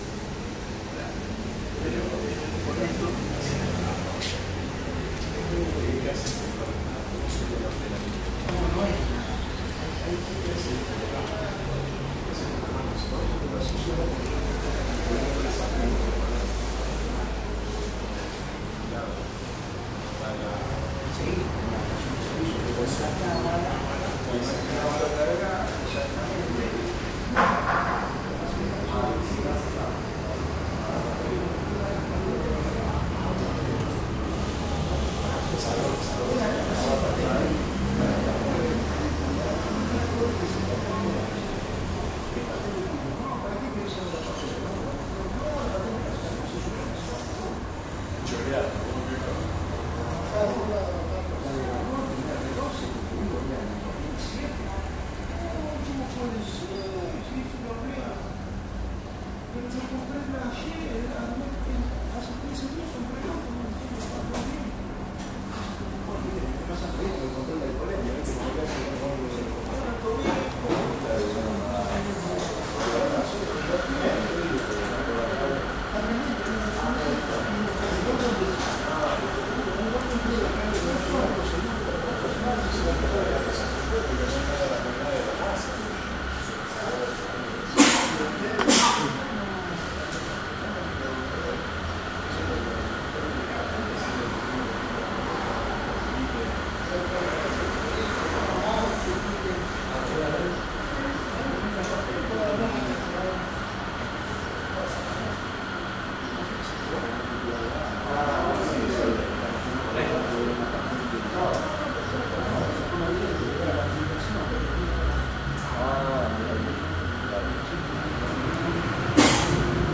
psr-shell-shop-av-pcias-unidas-y-av-p-peron.mp3